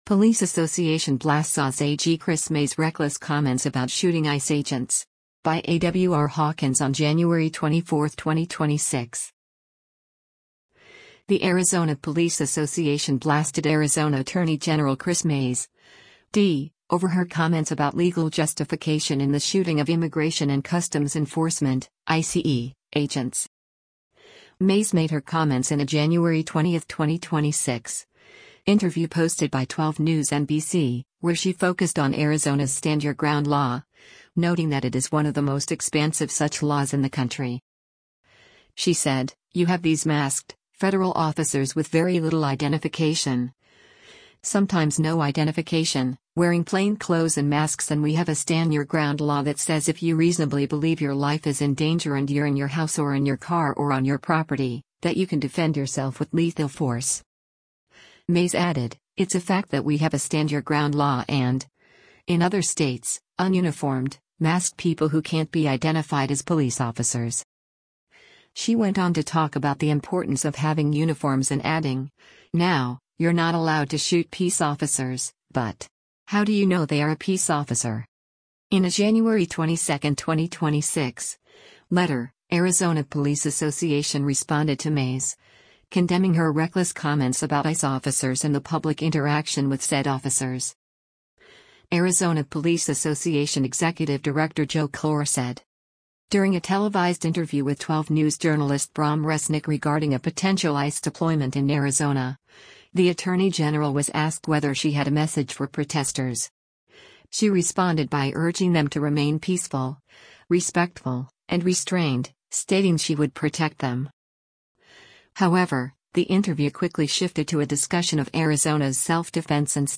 Mayes made her comments in a January 20, 2026, interview posted by 12 News NBC, where she focused on Arizona’s “Stand Your Ground” law, noting that it is one of the most expansive such laws in the country.